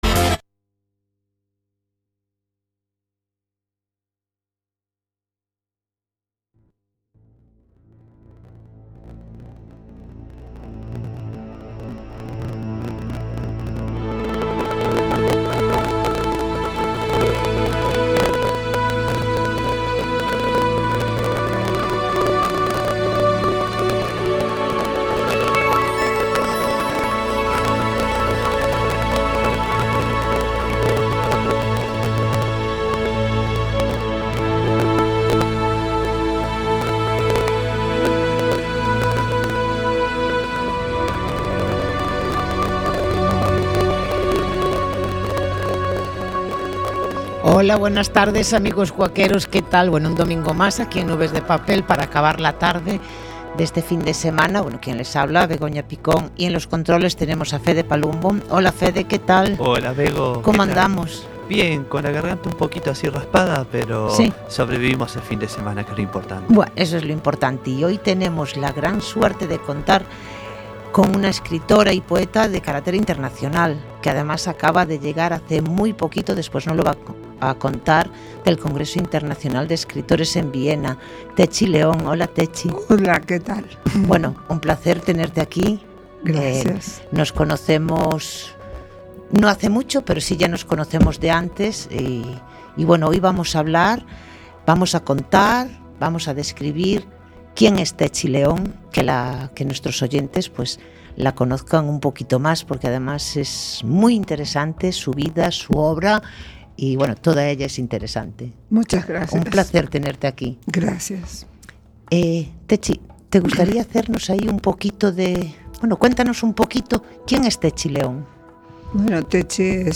En nuestro programa Nubes de Papel abordaremos diversos temas literarios, entrevistas con autores y autoras, pasearemos por el mundo de la pintura, escultura y música de nuestra ciudad.